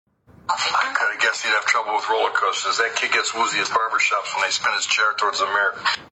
What’s he saying in this clip? Can you make out what’s being said?
I have an unclear audio recording and three possible transcript versions.